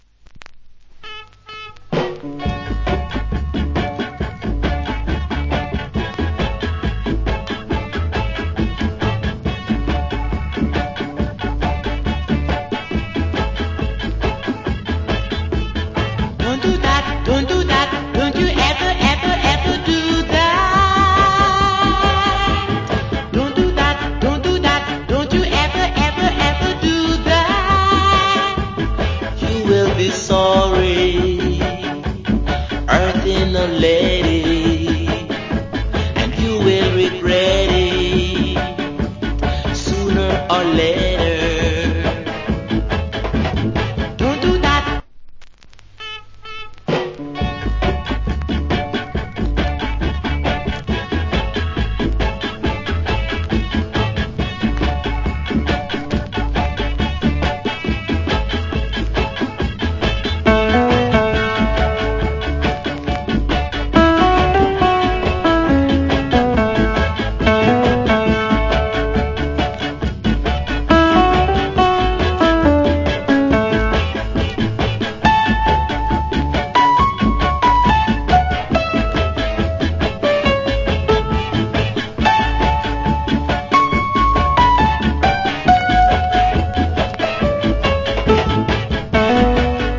Wicked Early Reggae Vocal.